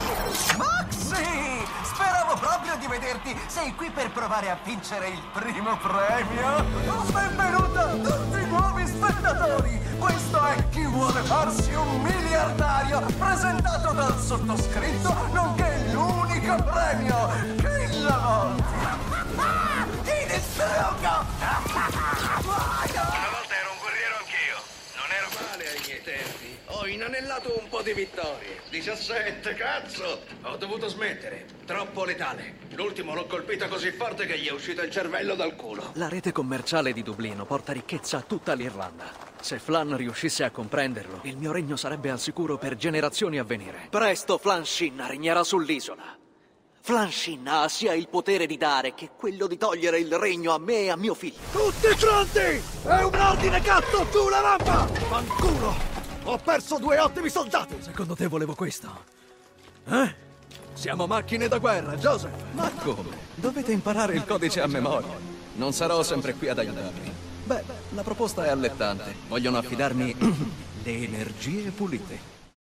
Male
Confident, Corporate, Energetic, Engaging, Friendly, Funny, Sarcastic, Streetwise, Upbeat, Versatile, Warm
TV-RADIO COMMERCIALS.mp3
Microphone: Neumann TLM-103